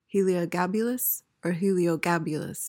PRONUNCIATION:
(hee-lee-uh/oh-GAB-uh-luhs)